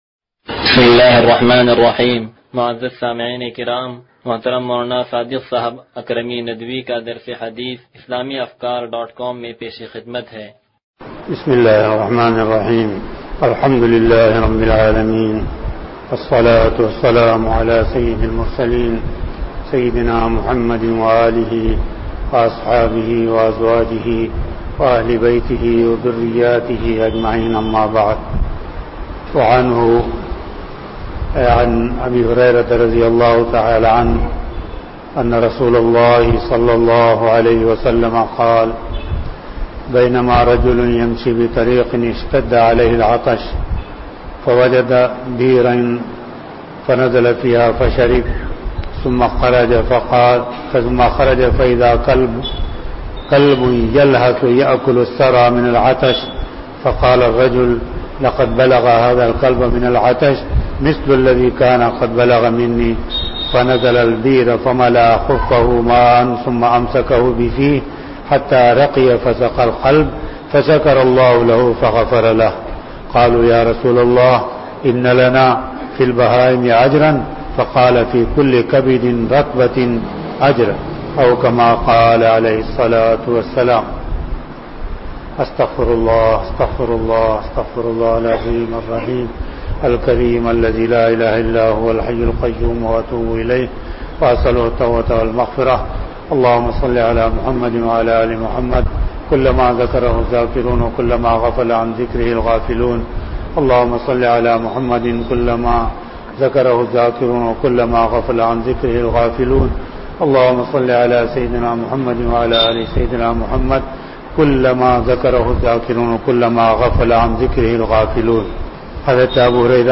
درس حدیث نمبر 0134